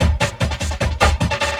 45 LOOP 03-R.wav